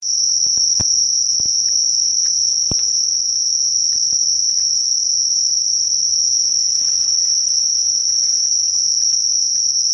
乡村的夜晚
描述：有些人觉得这听起来很轻松。我在这里住了足够长的时间让它疯狂。我只是睡觉，请闭嘴蟋蟀
标签： 蟋蟀 昆虫 青蛙 性质 现场记录
声道立体声